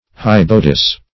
Search Result for " hybodus" : The Collaborative International Dictionary of English v.0.48: Hybodus \Hyb"o*dus\, n. [NL.